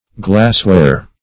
Glassware \Glass"ware\, n.